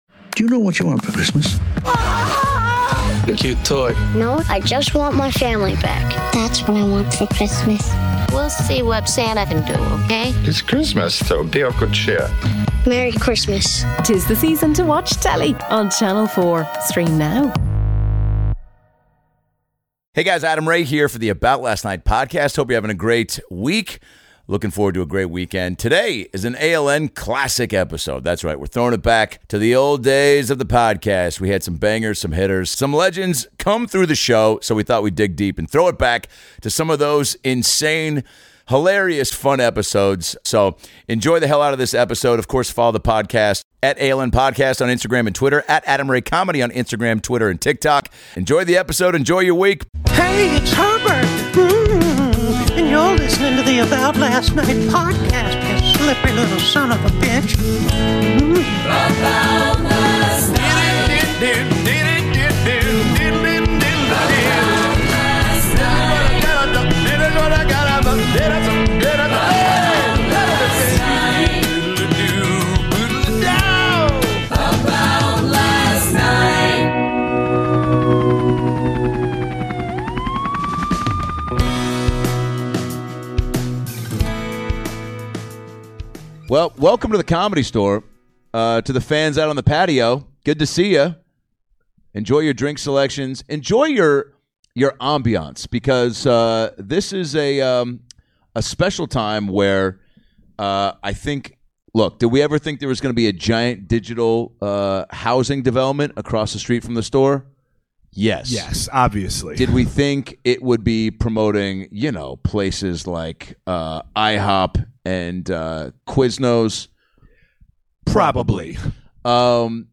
LIVE from THE COMEDY STORE Original Room, the boys cover everything from quarantine activities, to Grumpy Cat's bday party, to his new role playing Jamie Foxx's best friend, and some crowd work through the window!